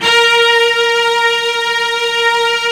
55u-va08-A#3.aif